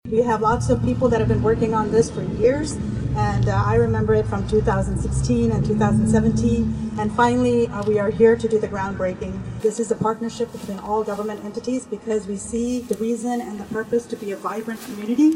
Mayor Usha Reddi spoke at the groundbreaking.